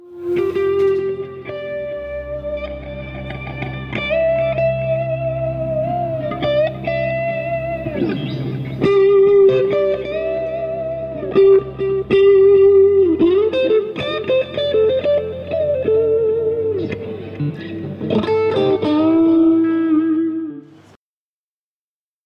clean intro tone from 2015 rehearsal